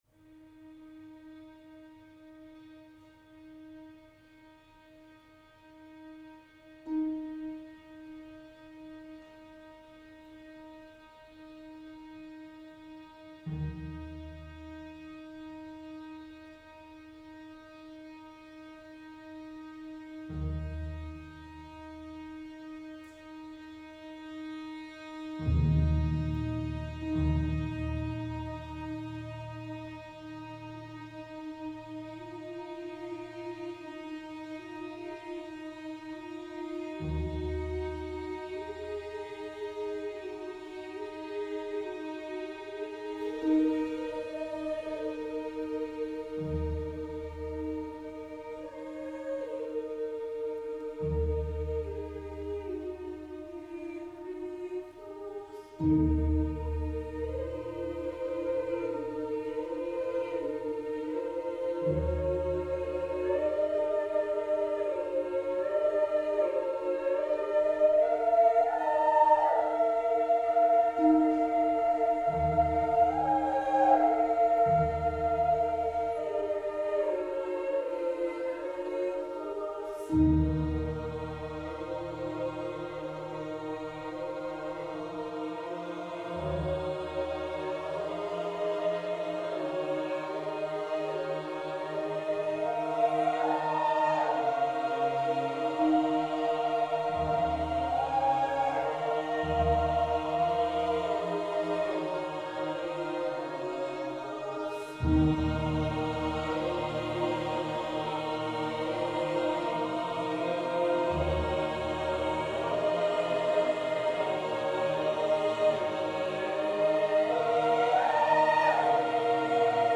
mezzosoprán
pre sólový ženský hlas, zbor a orchester